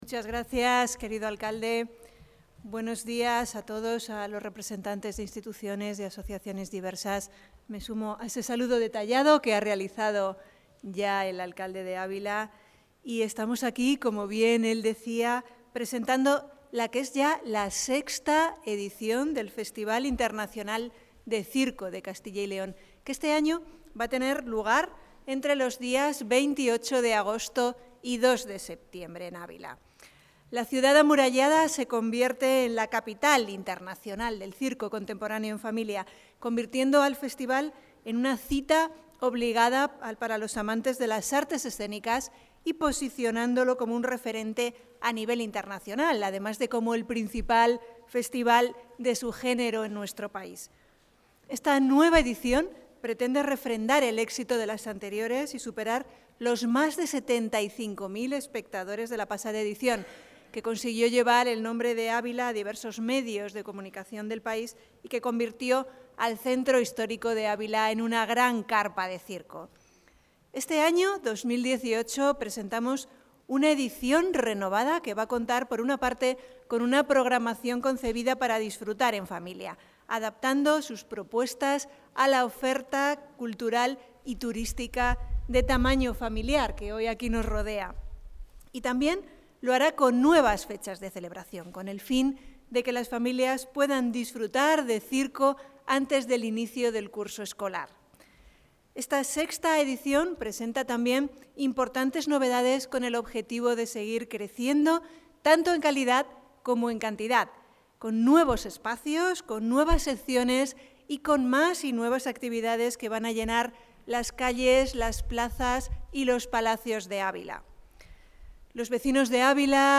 Declaraciones de la directora general de Políticas Culturales.